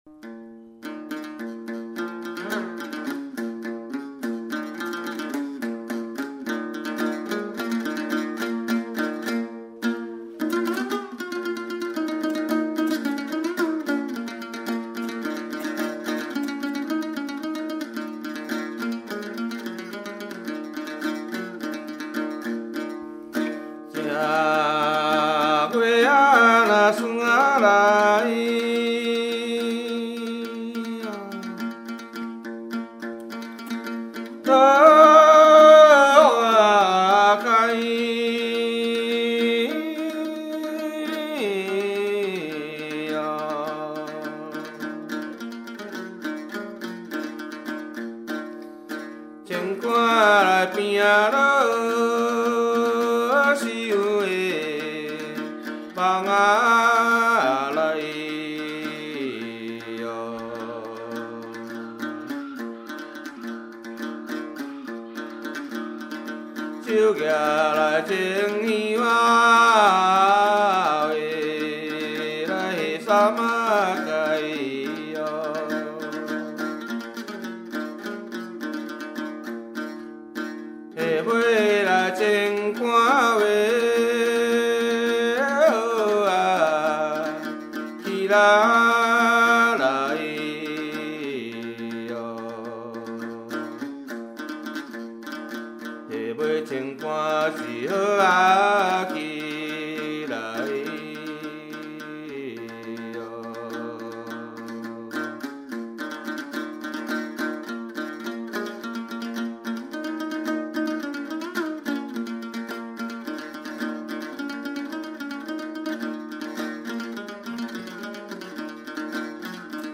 ◎制作群 ： 演出：月琴,壳仔弦｜
独奏